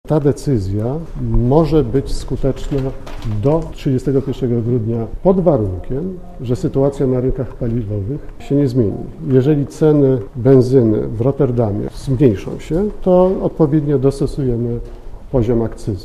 Mówi minister finansów, Mirosław Gronicki